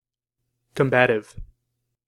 Ääntäminen
IPA : /ˈkɒm.bə.tɪv/